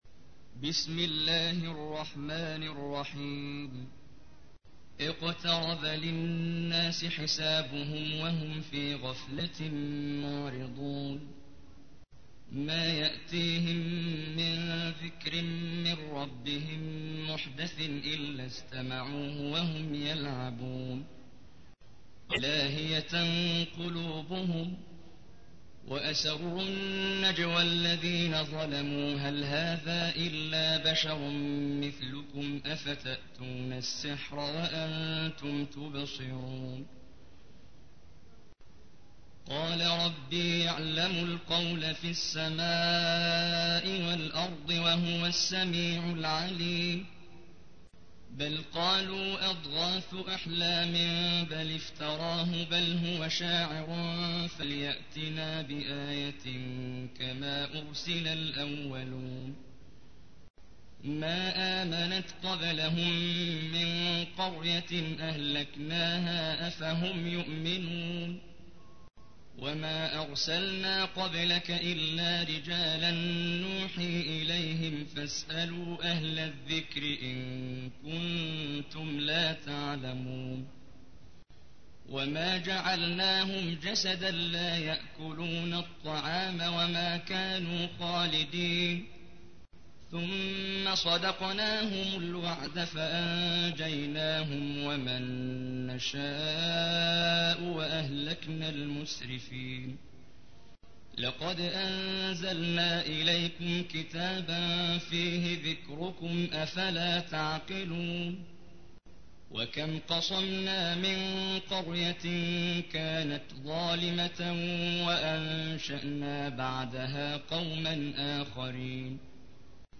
تحميل : 21. سورة الأنبياء / القارئ محمد جبريل / القرآن الكريم / موقع يا حسين